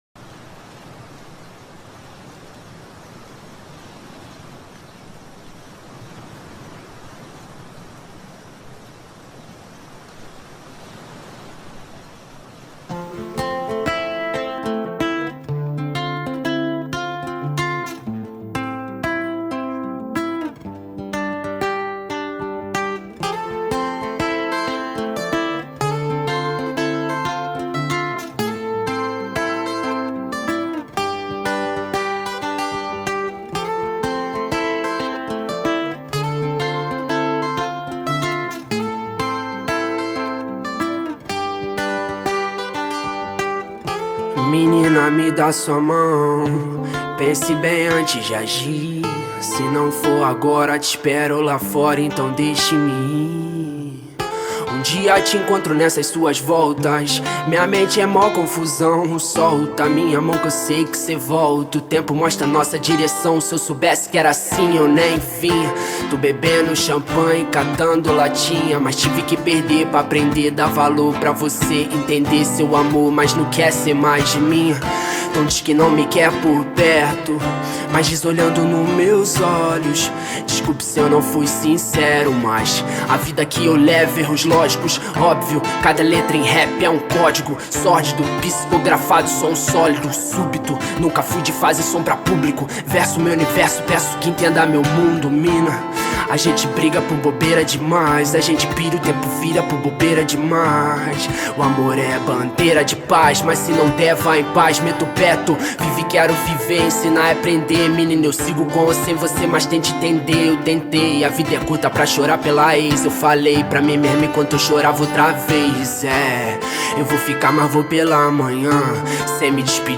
2024-03-21 22:11:29 Gênero: Hip Hop Views